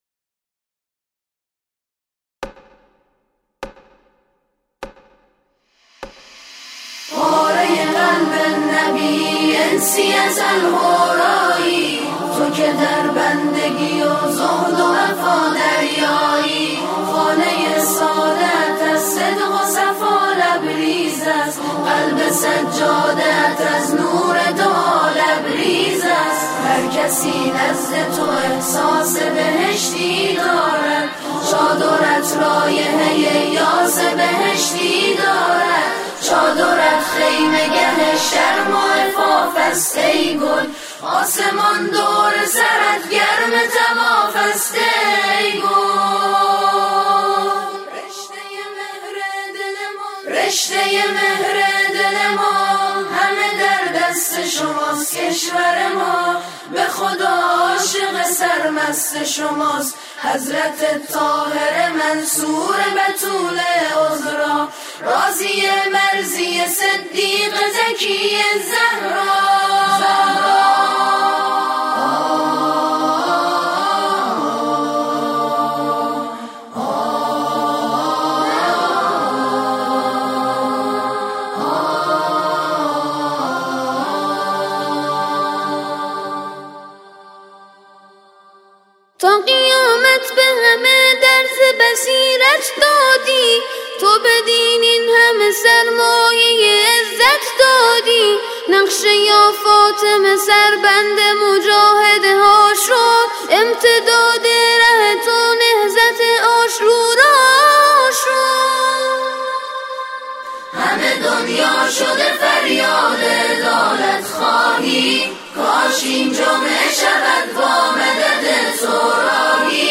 نسخه آکاپلا
همخوانی